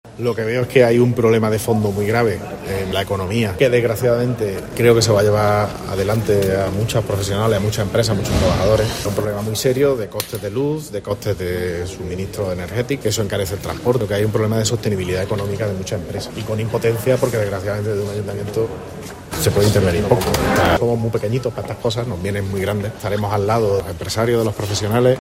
José María Bellido, alcalde de Córdoba